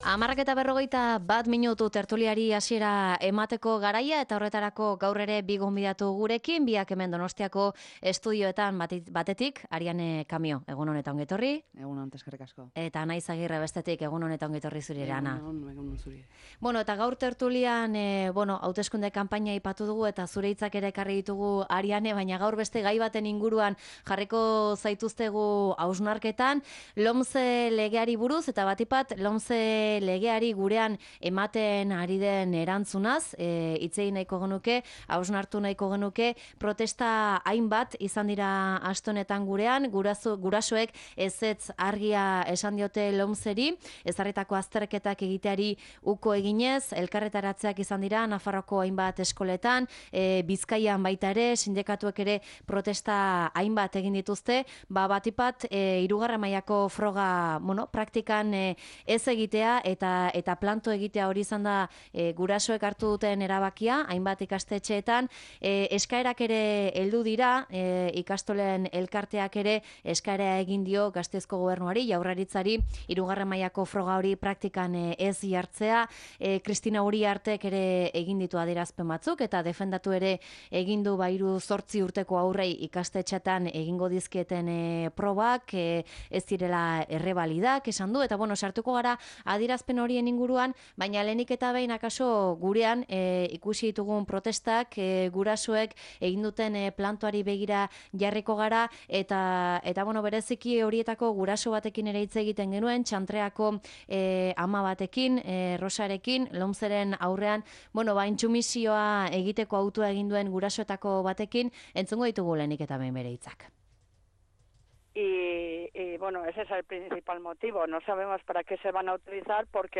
Tertulia: LOMCEren aurrean desobedientzia nahikoa gurean?